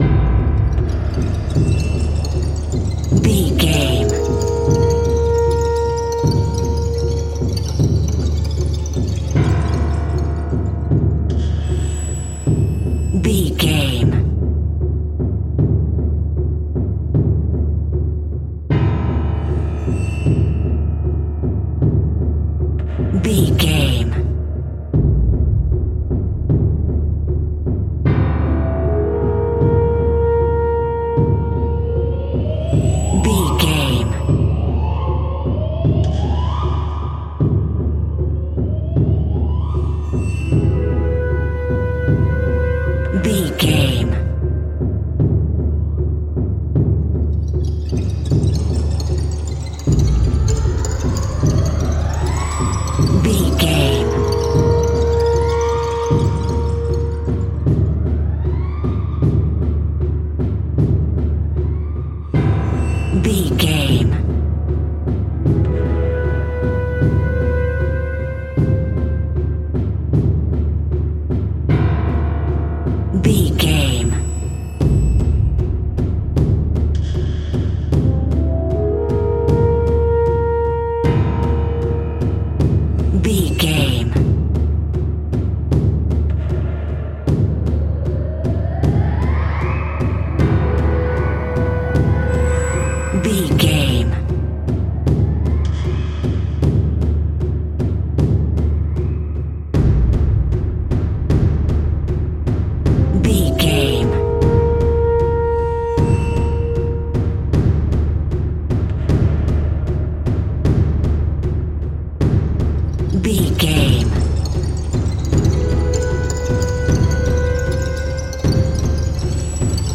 Atonal
scary
tension
ominous
dark
suspense
haunting
eerie
drums
synthesiser
horror
ambience
pads
eletronic